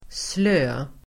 Uttal: [slö:]